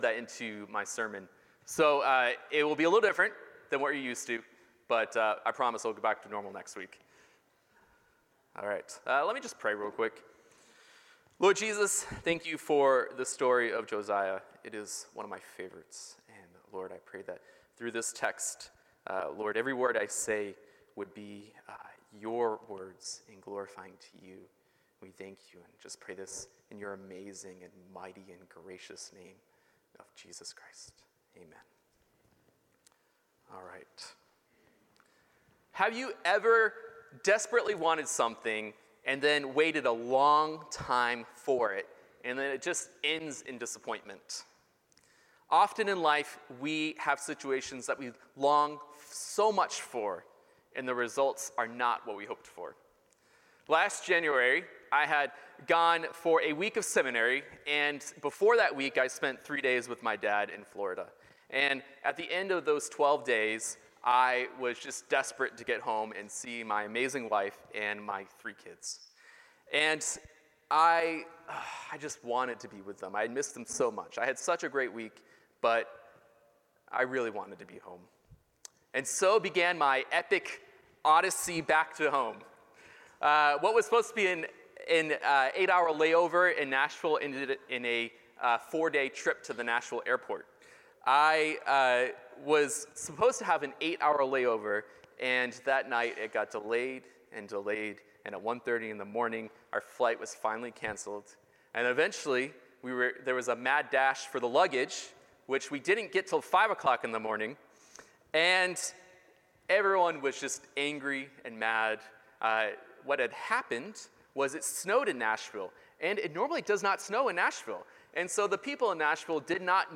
Passage: 2 Kings 22 Sermon